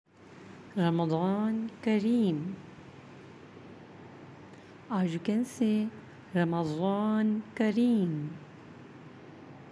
How To Say Tutorial